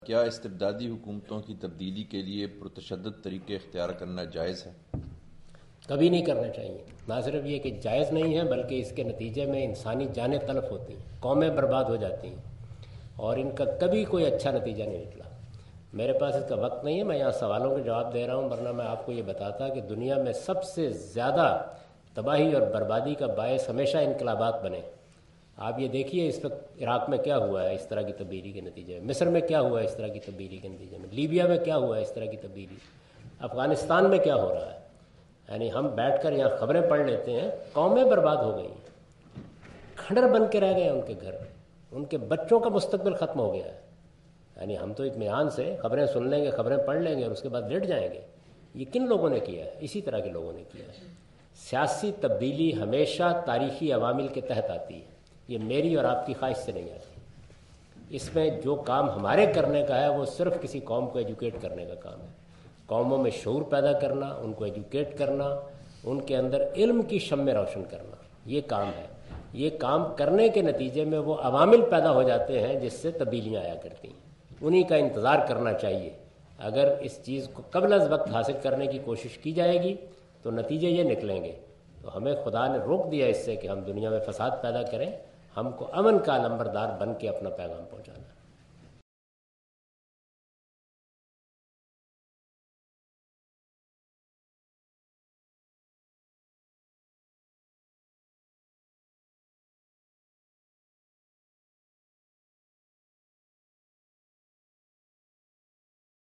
Javed Ahmad Ghamidi answer the question about "changing rulers through violence" during his visit to Queen Mary University of London UK in March 13, 2016.
جاوید احمد صاحب غامدی اپنے دورہ برطانیہ 2016 کےدوران کوئین میری یونیورسٹی اف لندن میں "پر تشدد طریقوں سے حکومت کو بدل دینا" سے متعلق ایک سوال کا جواب دے رہے ہیں۔